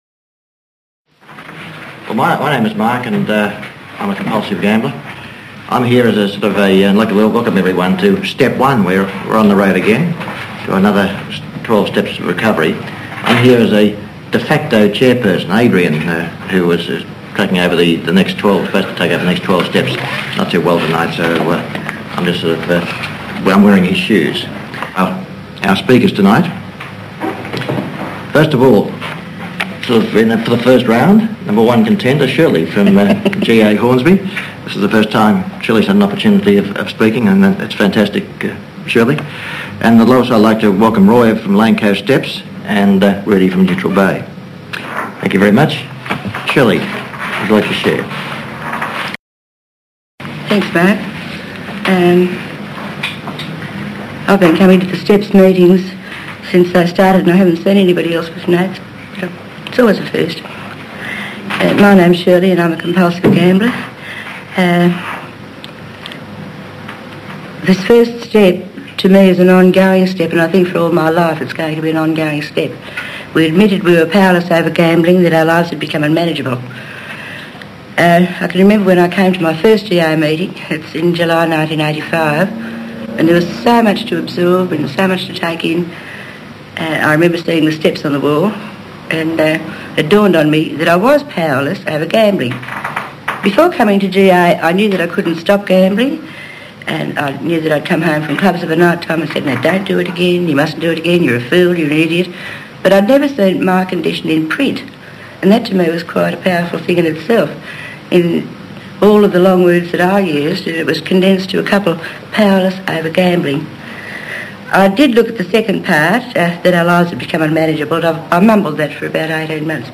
GA Step One – Featured GA Speaker Audios